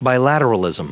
Prononciation du mot bilateralism en anglais (fichier audio)
Prononciation du mot : bilateralism